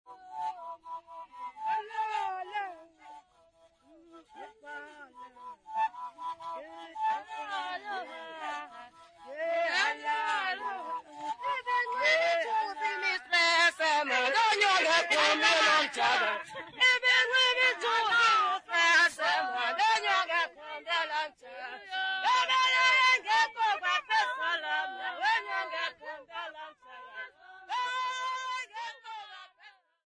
Folk music South Africa
Folk songs, Xhosa South Africa
Stringed instrument music South Africa
Africa South Africa Grahamstown , Eastern Cape sa
field recordings
Traditional song accompanied by the Xhosa Mrhube bow performed for Rhodes University symposium.